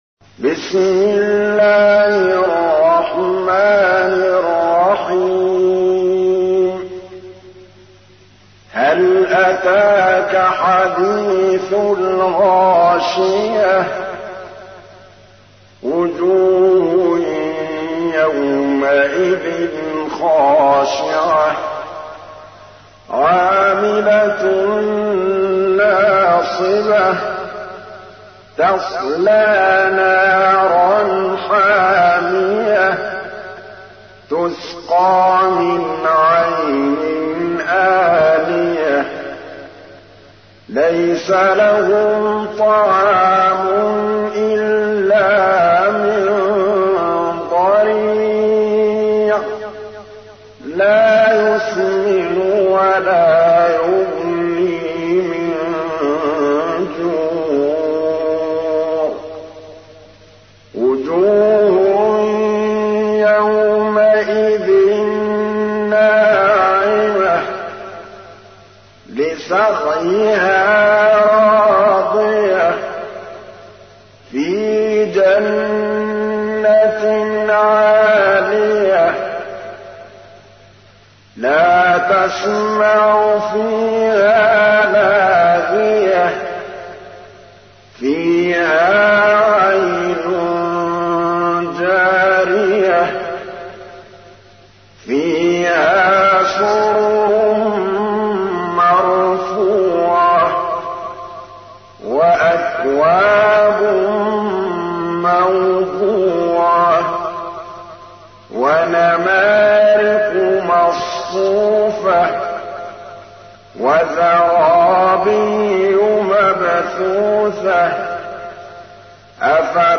تحميل : 88. سورة الغاشية / القارئ محمود الطبلاوي / القرآن الكريم / موقع يا حسين